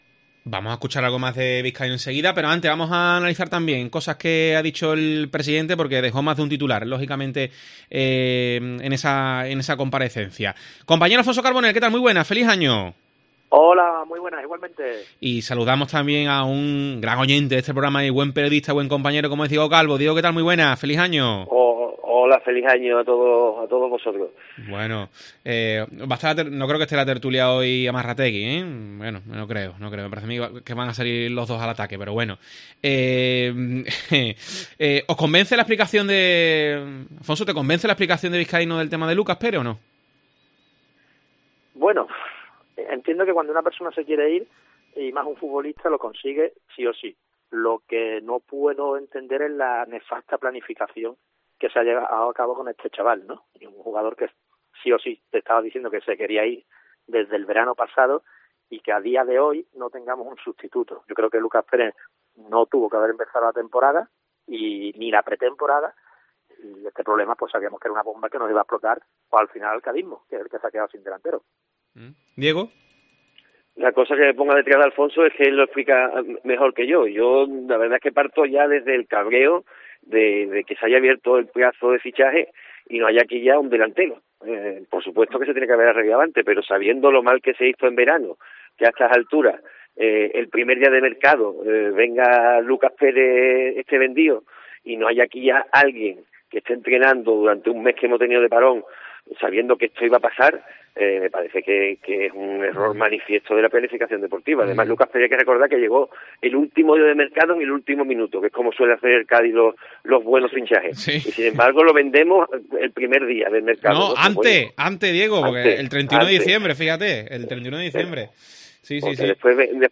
ESCUCHA EL DEBATE DE DEPORTES COPE